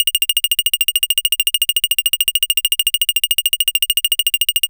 70s Random 102-F.wav